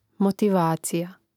motivácija motivacija